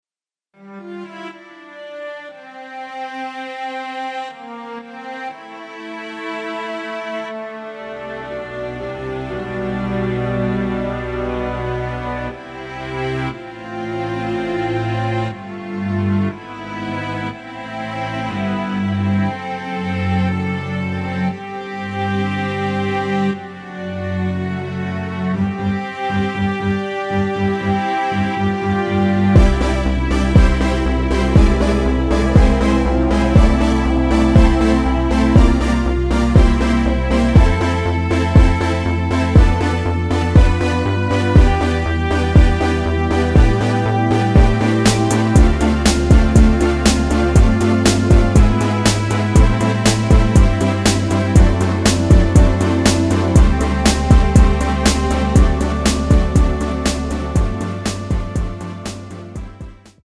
엔딩이 페이드 아웃이라 엔딩을 만들어 놓았습니다.
앞부분30초, 뒷부분30초씩 편집해서 올려 드리고 있습니다.
중간에 음이 끈어지고 다시 나오는 이유는